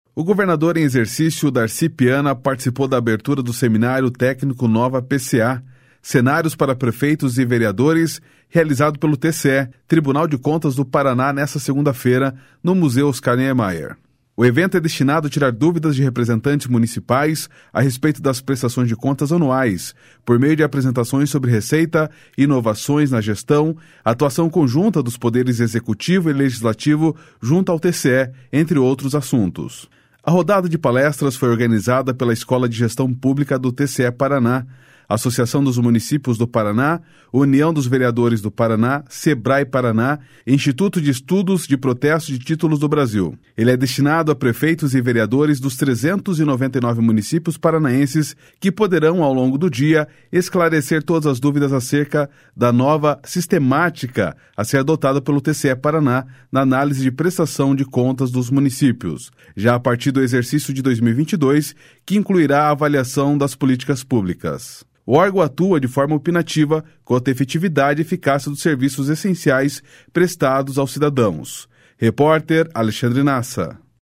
O governador em exercício Darci Piana participou da abertura do seminário técnico Nova PCA: Cenários para Prefeitos e Vereadores, realizado pelo TCE, Tribunal de Contas do Paraná, nesta segunda-feira, no Museu Oscar Niemeyer. O evento é destinado a tirar dúvidas de representantes municipais a respeito das prestações de contas anuais, por meio de apresentações sobre receita, inovações na gestão, atuação conjunta dos poderes Executivo e Legislativo junto ao TCE, entre outros assuntos.